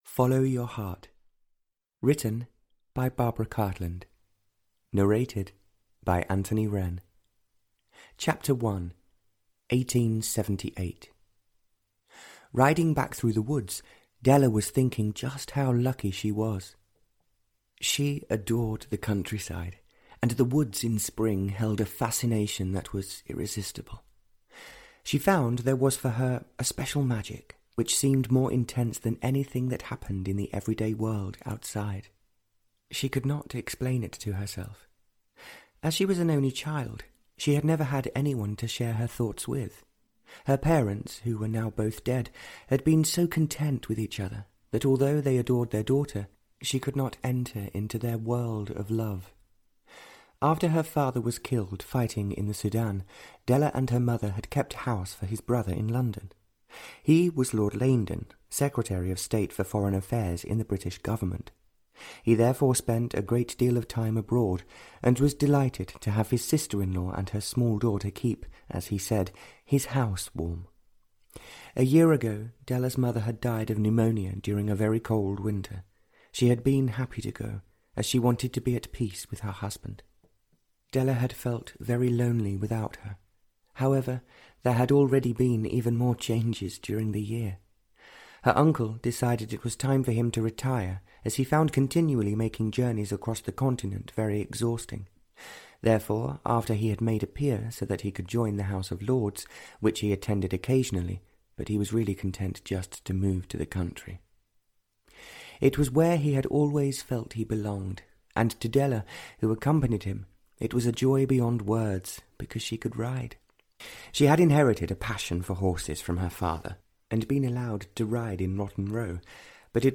Follow Your Heart (Barbara Cartland’s Pink Collection 45) (EN) audiokniha
Ukázka z knihy